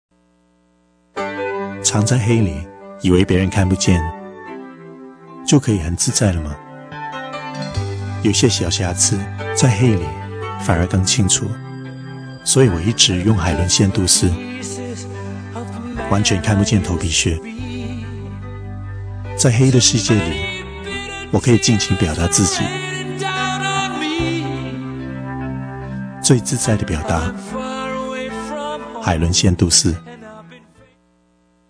2004 Tony Leung advertised for Head & Shoulders
Click here to listen to the head & shoulders commercial